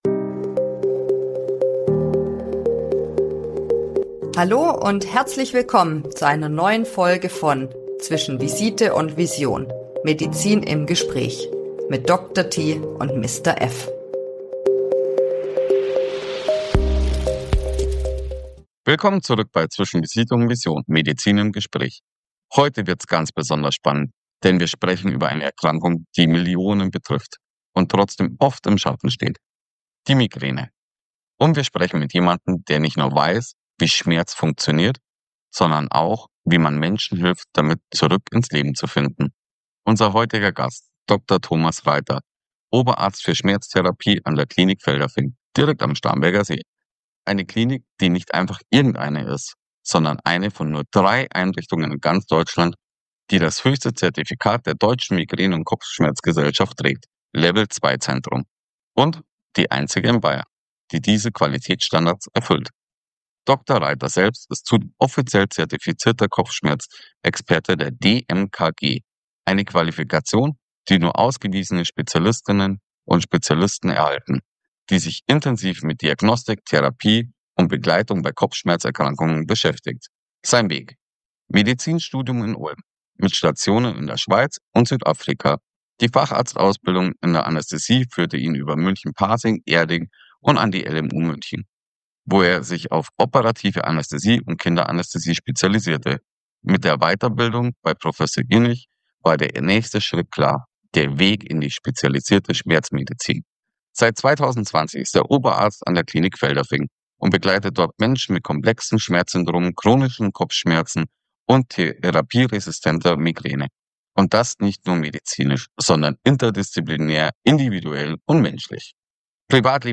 Willkommen zurück bei Zwischen Visite & Vision – Medizin im Gespräch. Heute sprechen wir über eine Erkrankung, die Millionen betrifft – aber oft unterschätzt wird: Migräne.